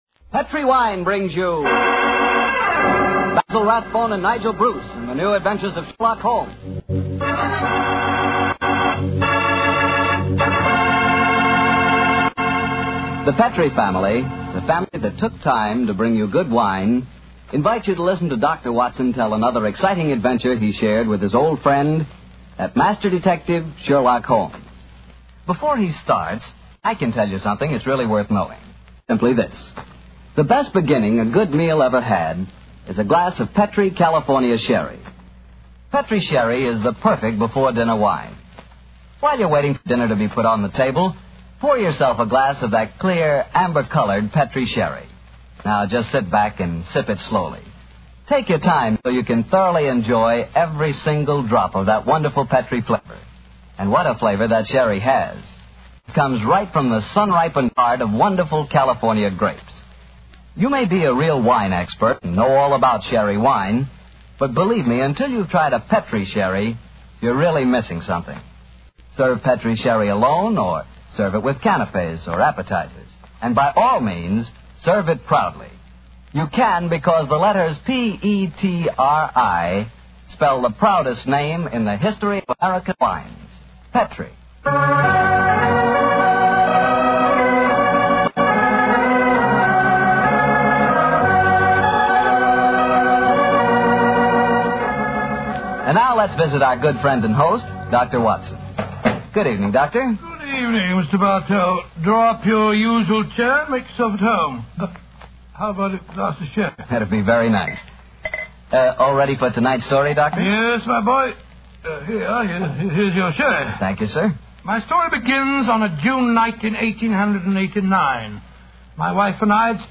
Radio Show Drama with Sherlock Holmes - The Man With The Twisted Lip 1946